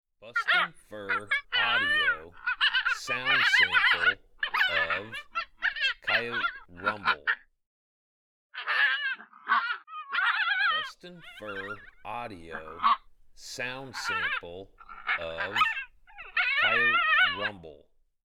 BFA Coyote Rumble
Young Coyotes establishing dominance. Excellent territorial sound.
• Product Code: pups and fights